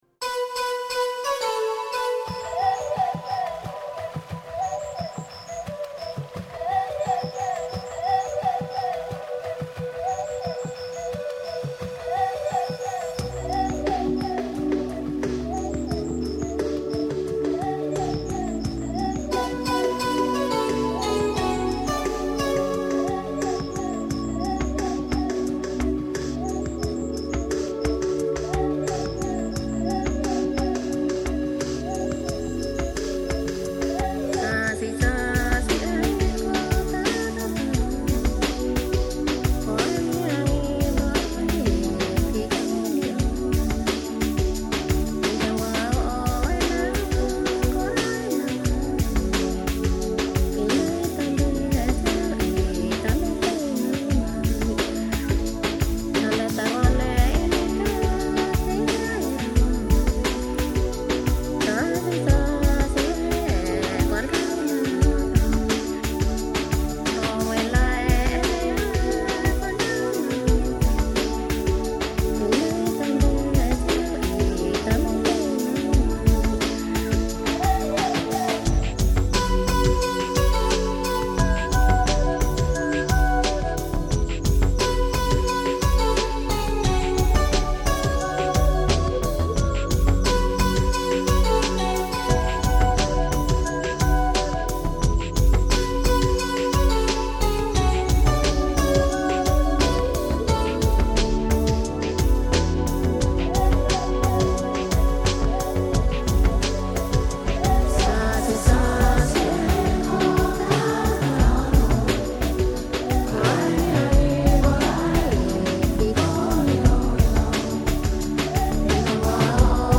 再以现代的电子合成乐器掺进现代音乐的质感制作
融合了现代电子键盘乐器、各民族传统音乐以及自己的创作